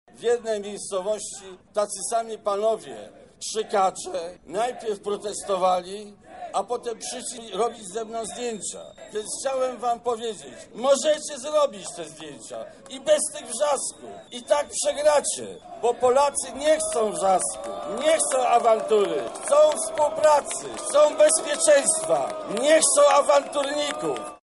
Okrzyki, skandowanie i gwizdy – w takiej atmosferze prezydent Bronisław Komorowski spotkał się z mieszkańcami Lublina
Wystąpienie głowy państwa zakłócali między innymi członkowie Ruchu Narodowego i partii Korwin.